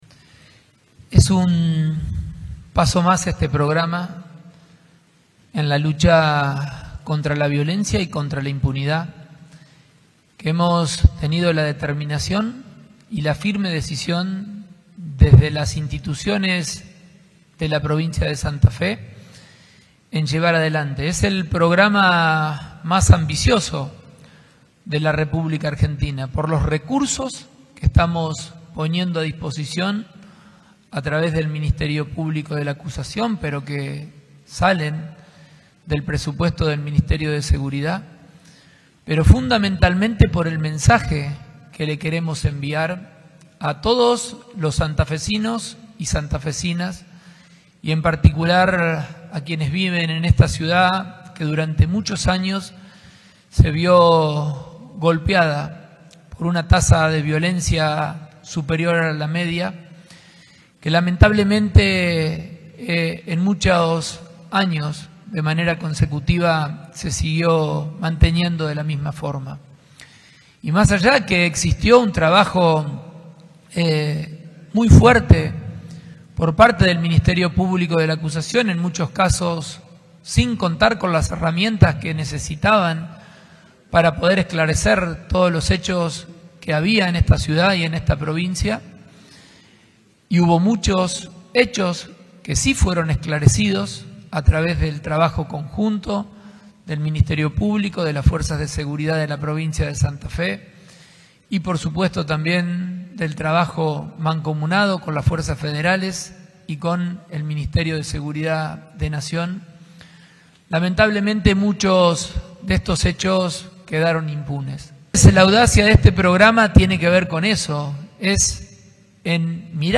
El acto se realizó en la Sede de Gobierno en Rosario, y participaron también el ministro de Justicia y Seguridad, Pablo Cococcioni, y la fiscal General del Ministerio Público de la Acusación (MPA), María Cecilia Vranicich.